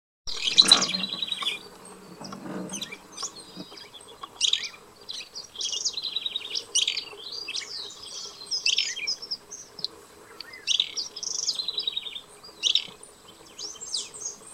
Barullero (Euscarthmus meloryphus)
Nombre en inglés: Fulvous-crowned Scrub Tyrant
Localidad o área protegida: Ceibas
Condición: Silvestre
Certeza: Vocalización Grabada
Barullero.mp3